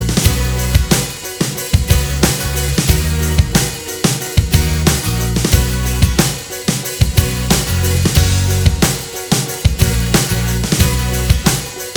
Minus Guitars Except Acoustic Pop (2010s) 3:05 Buy £1.50